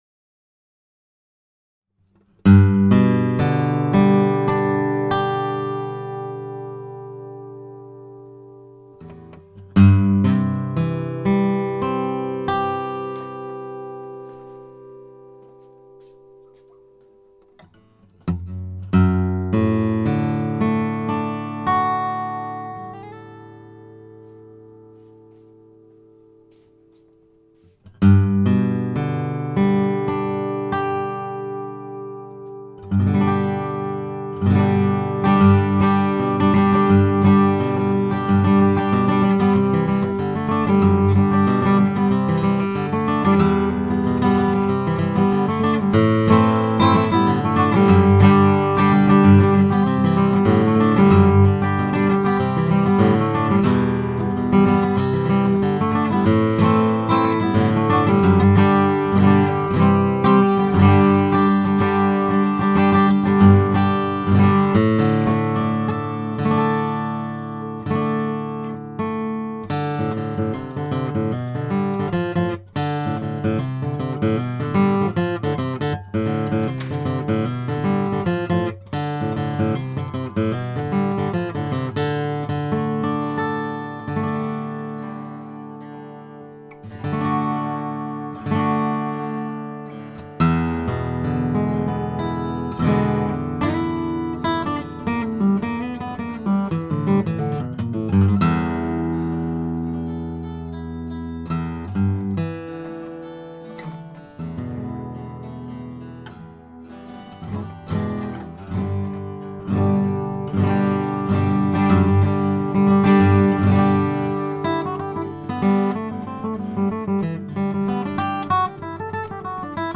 Many of these were made here in the shop about as simply as they could be done.
DS-M No165, a 12 fret dreadnought, powerful yet rich tone